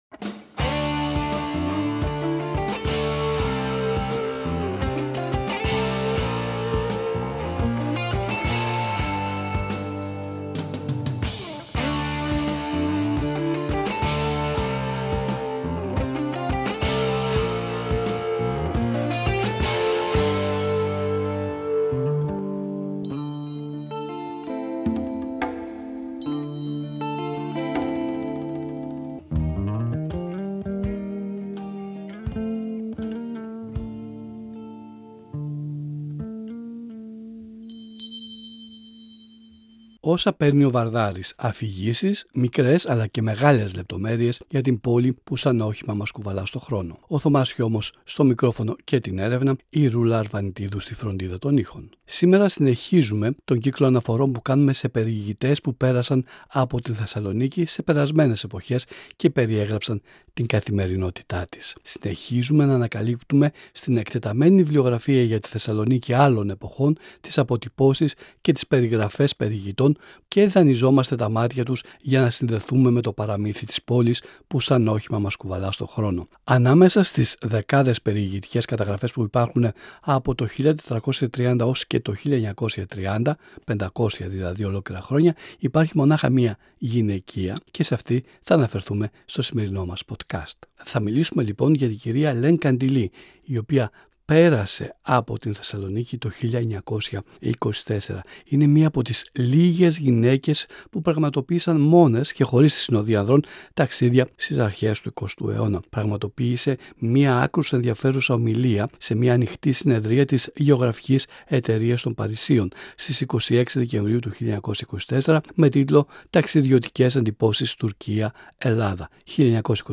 Αφηγήσεις, ιστοριες, μικρές και μεγάλες λεπτομέρειες για την πόλη που σαν όχημα μας κουβαλά στον χρόνο.